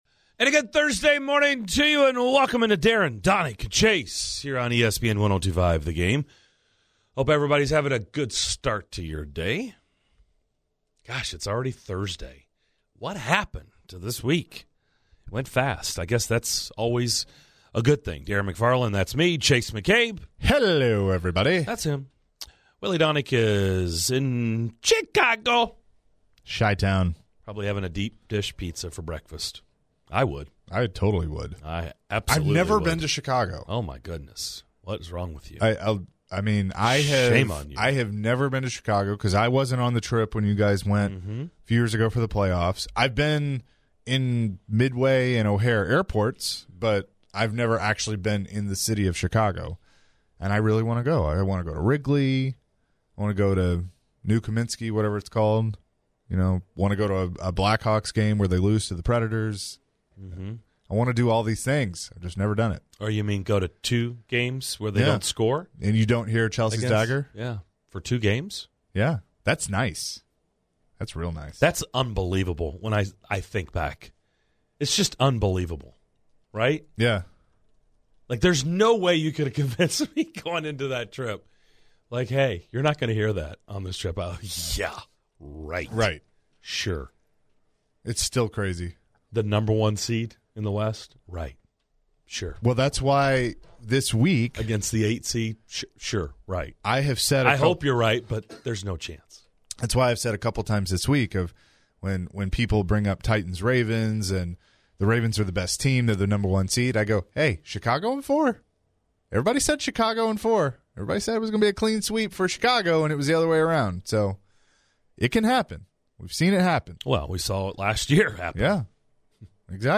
talk with NBC Sports hockey reporter Pierre McGuire about the Preds coaching change and what's next for Smashville's playoff hopes. The guys also reflect on P.K. Subban's time in Nashville and discuss the NFL's loaded quart...